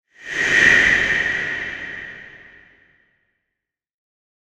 Ghost Whoosh Sound Effect
A swift and eerie spectral whoosh, perfect for adding a chilling atmosphere. Ideal for horror films, haunted house scenes, and Halloween projects.
Ghost-whoosh-sound-effect.mp3